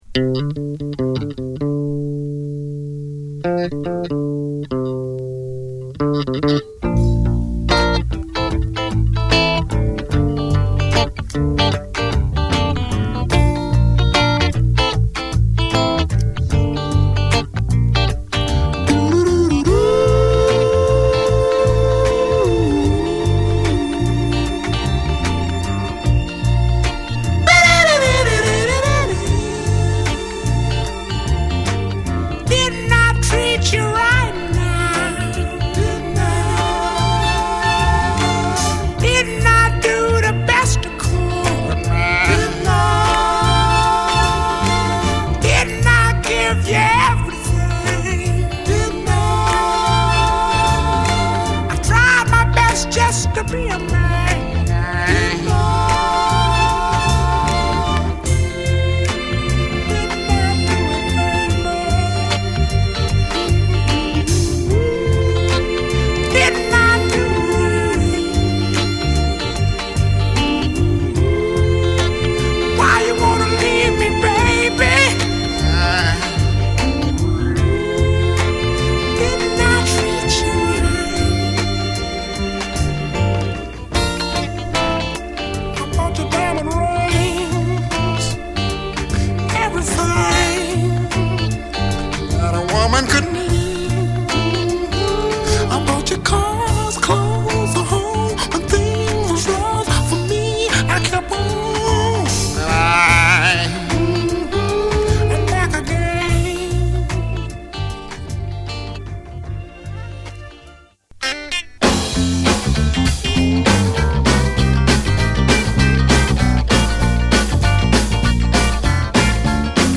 唯一無二のメロウでドリーミンなソウルを披露。